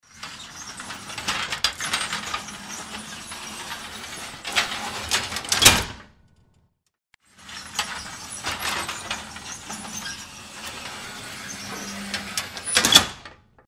Звуки ворот
8. Гаражные ворота